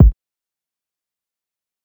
Kicks
Rack Kick1.wav